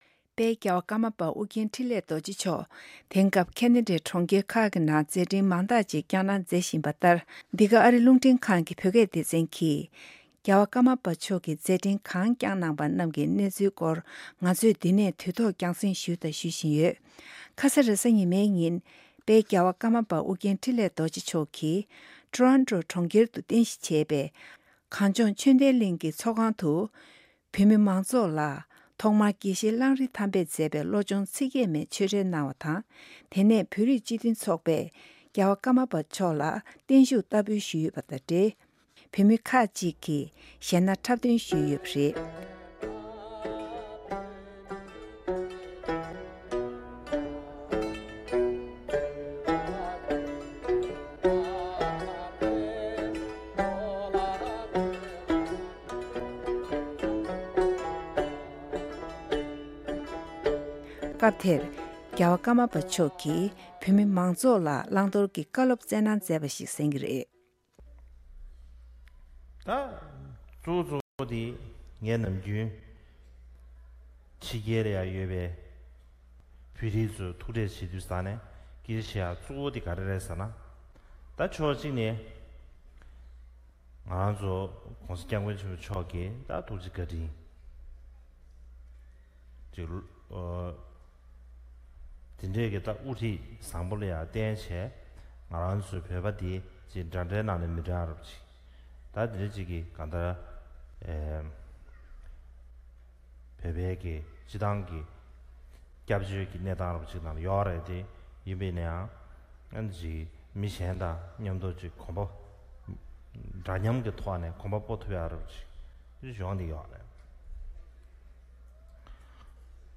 དཔལ་རྒྱལ་བ་ཀརྨ་པ་ཨོ་རྒྱན་འཕྲིན་ལས་རྡོ་རྗེ་མཆོག་གིས་ཊོ་རོན་ཊོའི་གྲོང་ཁྱེར་དུ་ཡོད་པའི་དགའ་ལྡན་ཆོས་ལྡན་གླིང་དུ་བོད་མི་མང་ཚོགས་ལ་ཆོས་འབྲེལ་དང་ བོད་རིགས་སྤྱི་མཐུན་ཚོགས་པས་བརྟན་བཞུགས་བསྟར་འབུལ་ཞུས་ཡོད་ཅིང་ སྐབས་དེར་ཀརྨ་པ་མཆོག་གིས་བོད་མི་རྣམས་ལ་མཐུན་བསྒྲིལ་བྱེད་དགོས་པའི་ལམ་སྟོན་བཀའ་སློབ་གནང་བ་ཞིག་གསན་གྱི་རེད།